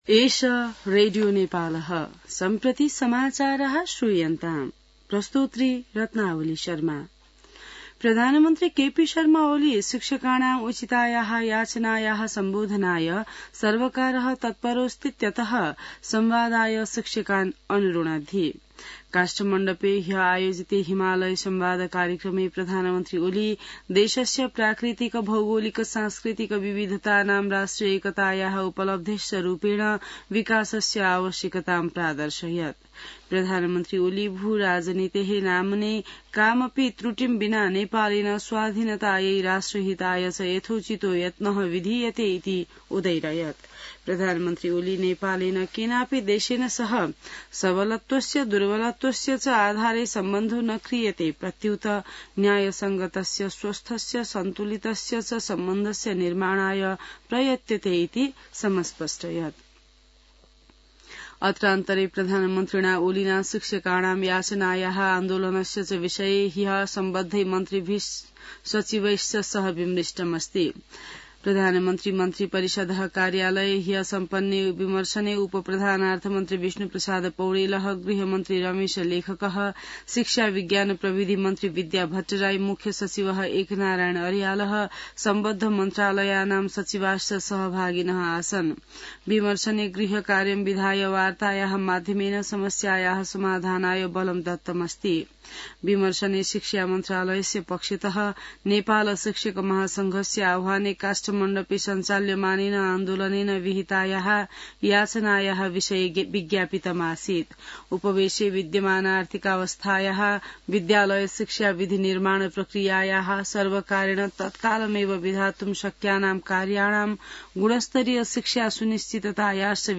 An online outlet of Nepal's national radio broadcaster
संस्कृत समाचार : २६ चैत , २०८१